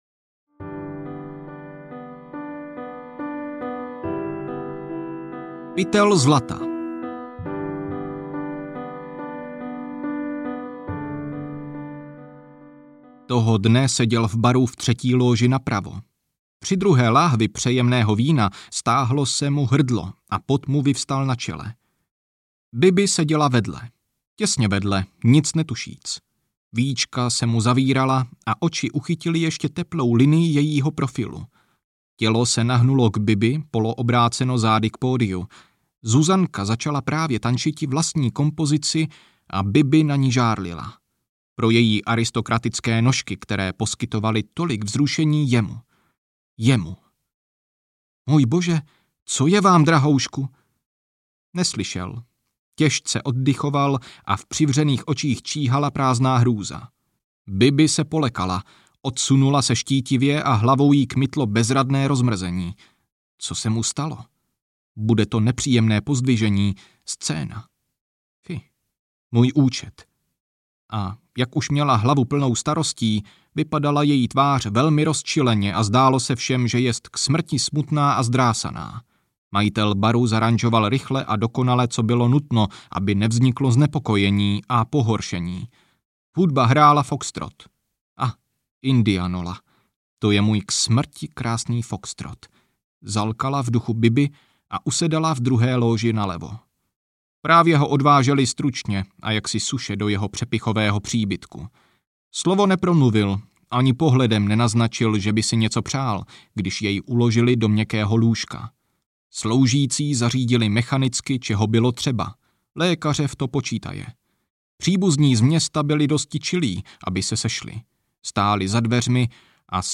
Vítr v ohradě audiokniha
Ukázka z knihy